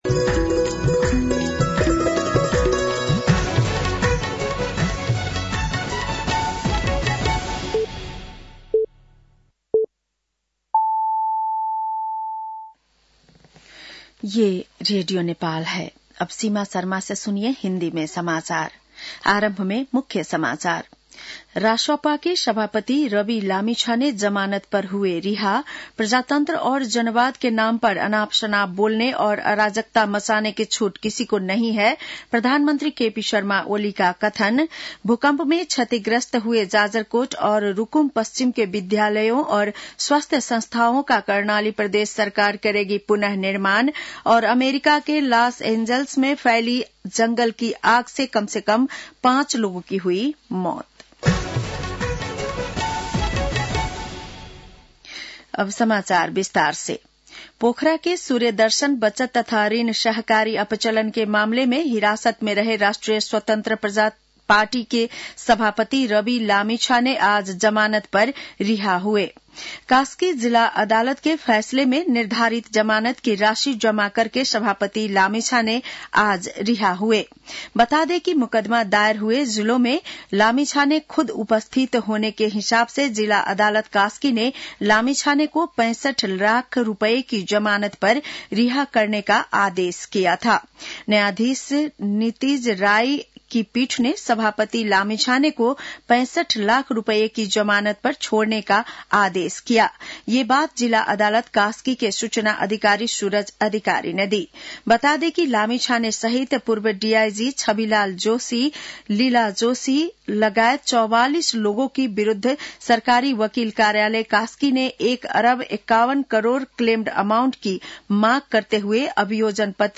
बेलुकी १० बजेको हिन्दी समाचार : २६ पुष , २०८१
10-PM-Hindi-NEWS-9-25.mp3